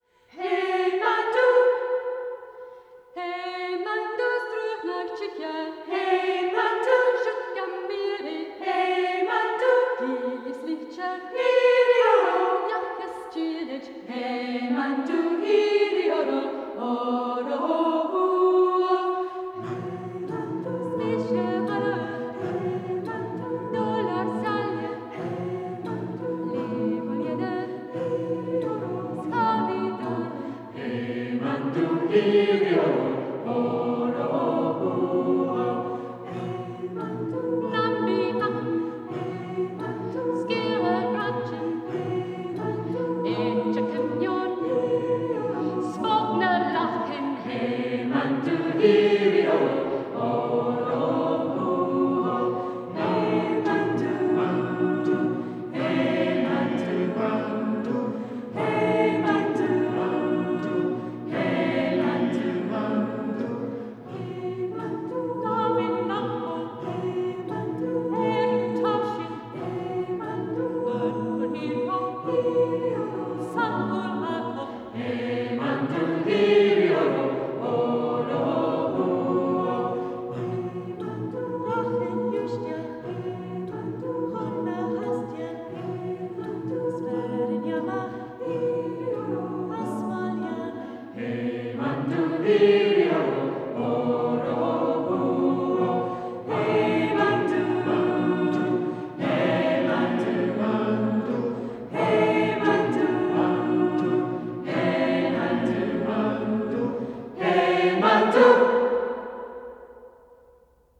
traditional Gaelic song